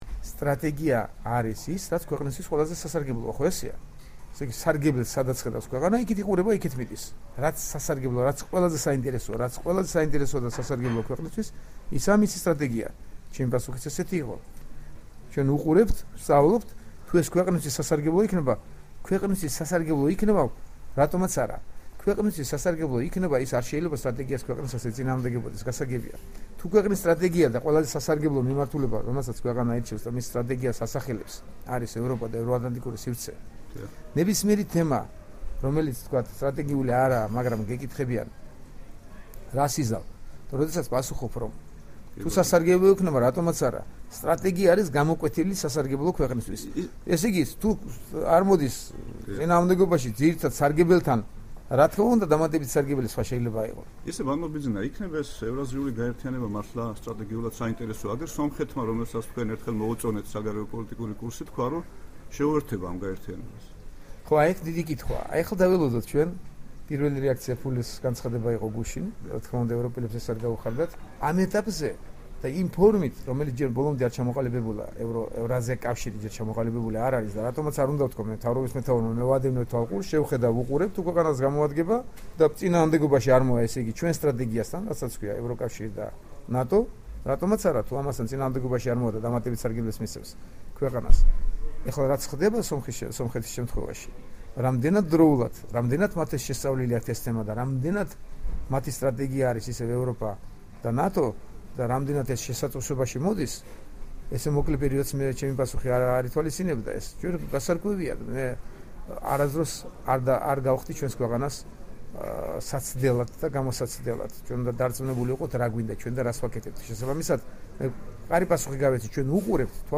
ინტერვიუ ბიძინა ივანიშვილთან